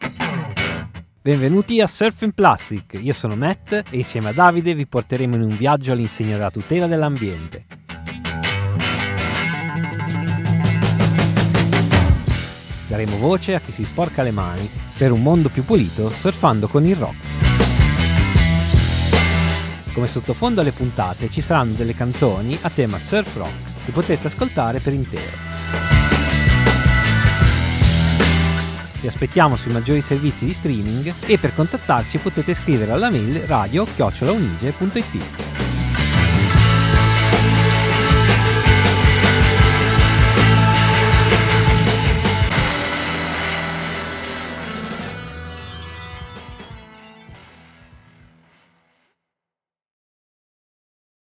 In ogni episodio inoltre sono presenti delle canzoni che potrete ascoltare per intero attraverso il link in descrizione.